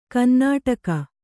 ♪ kannāṭaka